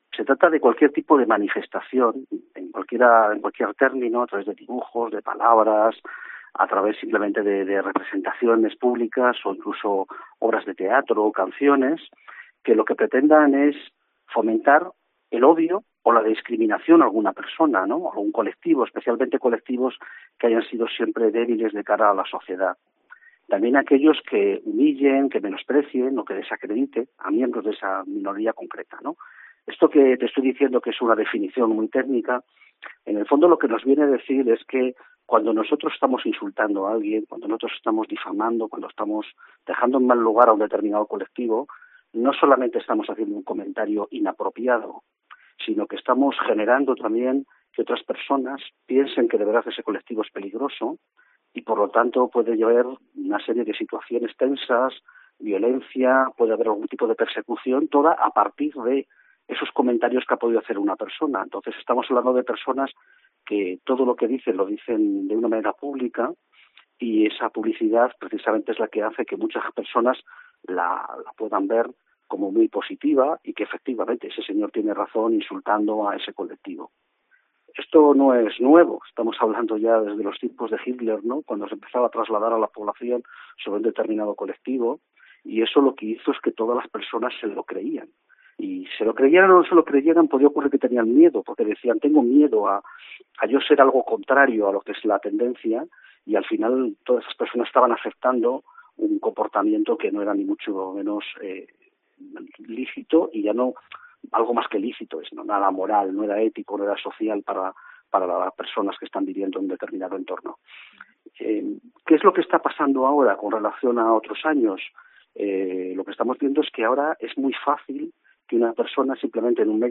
COPE Salamanca entrevista en COPE al abogado salmantino